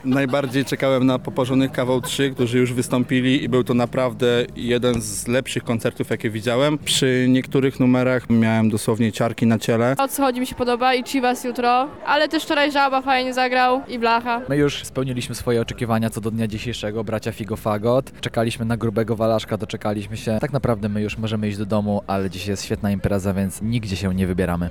Lublinalia, relacja 2